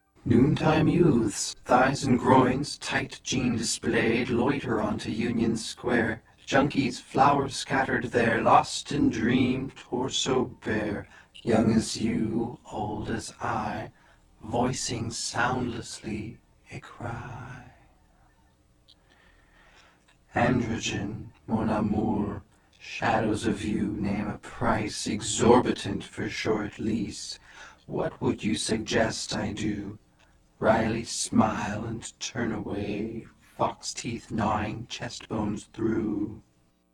Processing: 12 voi. granulated, Dur = 25 ms, 1:5 on "lost in dream", 1:1 on "cry", 1:4 on 2nd stanza, 1:2 on end